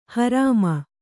♪ harāma